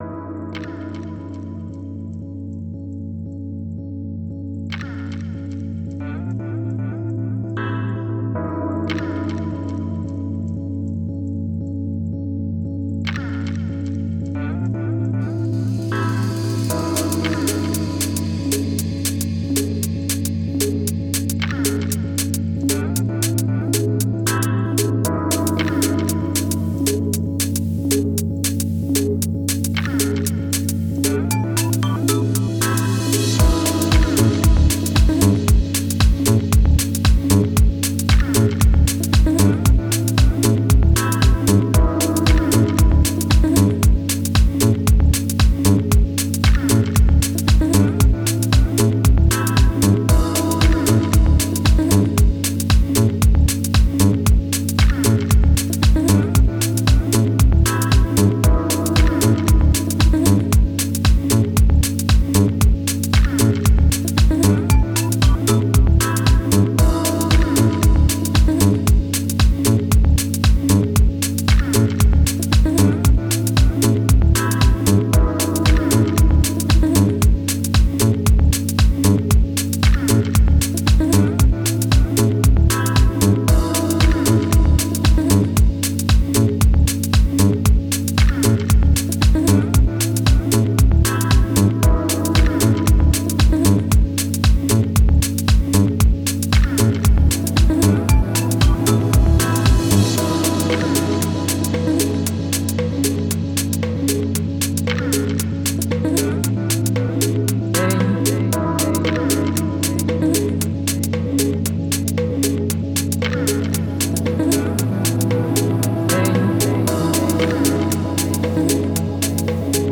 Genre: Deep Techno/Dub Techno.